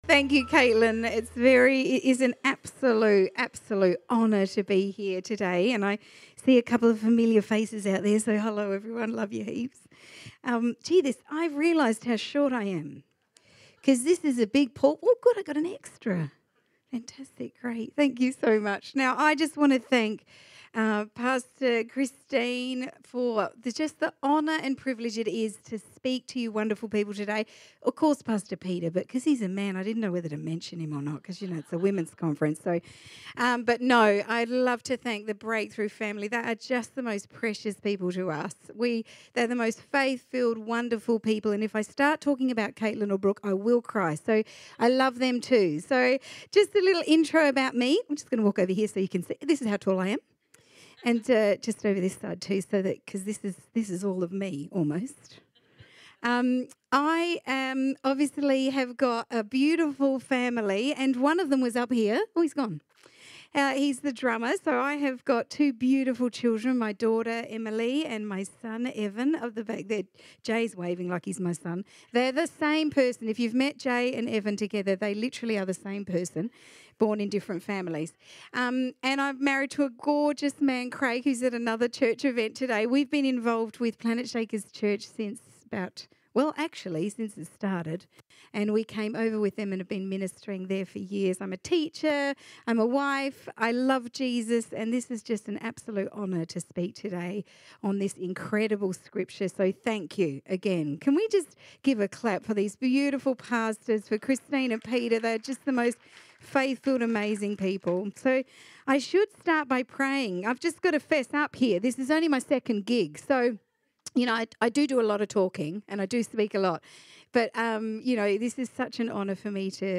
LADIES CONFERENCE - Session 1 Speaker